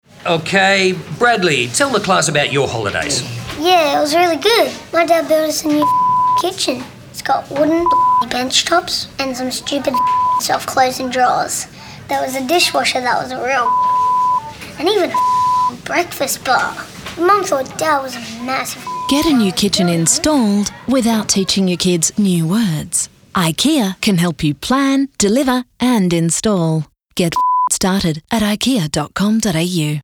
An ad for Ikea created by Three Drunk Monkeys has won the overall and single categories for the first round of the 2012 Siren Awards, organised by Commercial Radio Australia.